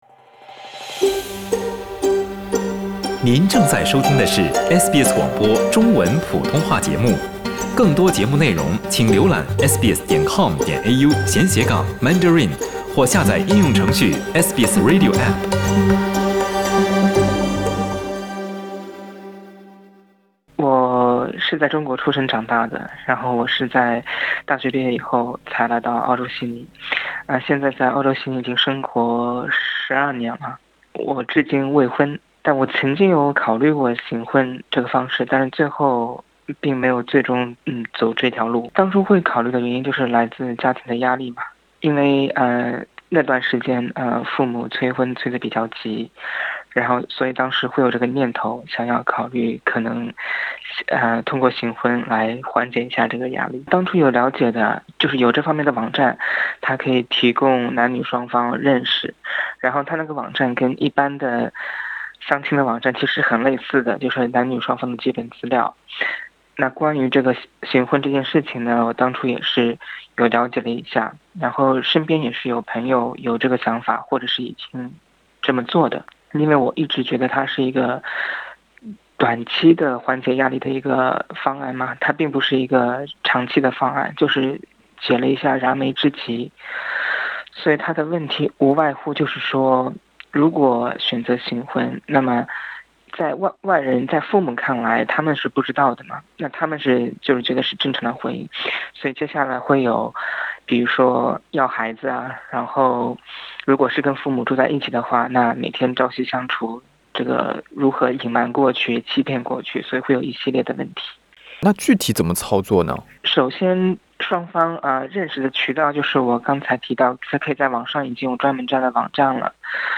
声音经过处理。